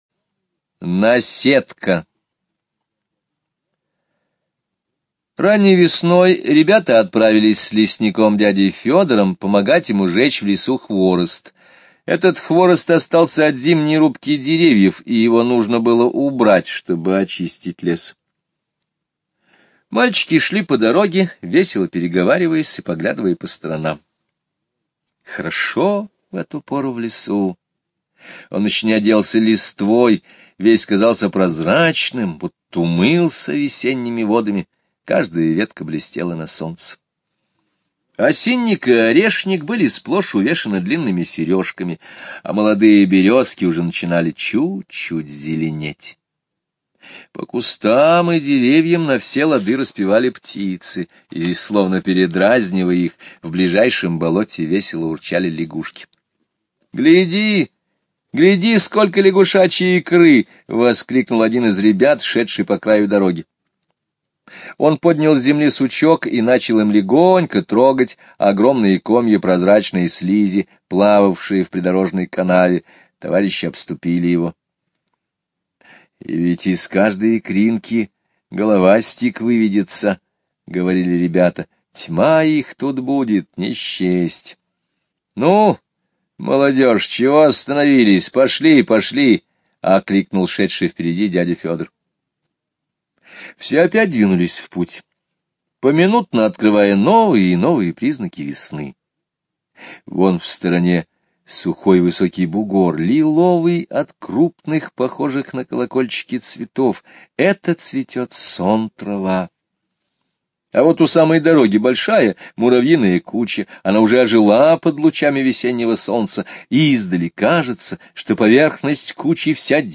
Слушайте аудио рассказ "Наседка" Скребицкого Г. онлайн на сайте Мишкины книжки. Однажды весной ребята с лесником пошли в лес на вырубку сжечь хворост. Случайно они разожгли костер на гнезде тетерки. skip_previous play_arrow pause skip_next ...